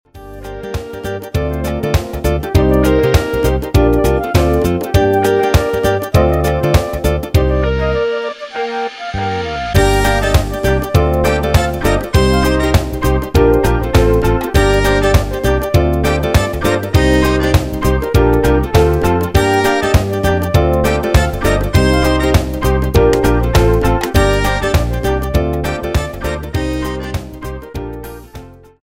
fichier midi